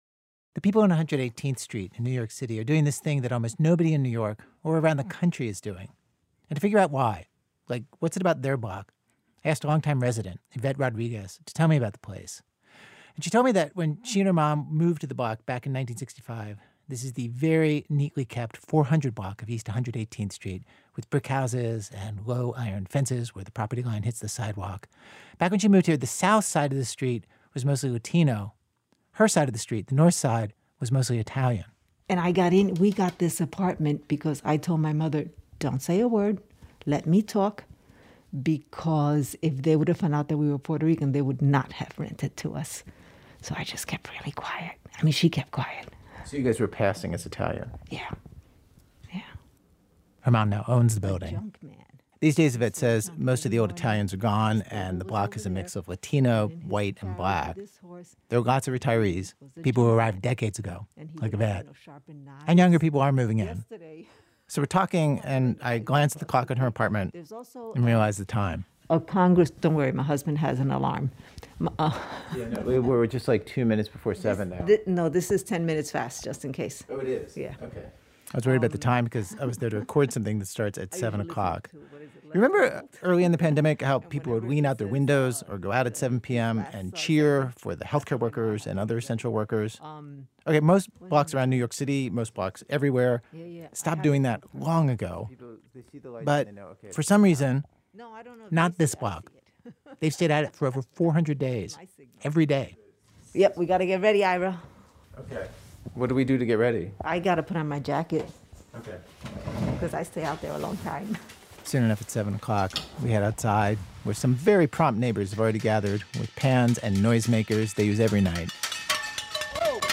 Note: The internet version of this episode contains un-beeped curse words.
Host Ira Glass goes to a block in New York City where, over a year into the pandemic, neighbors are still clapping for health care workers every night at 7 p.m. (7 minutes)